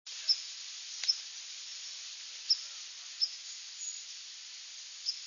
White-throated Sparrow, Sunfish Pond, Delaware Water Gap, NJ, 10/13/01, "peeps" flocked with Ruby-crowned Kinglet in background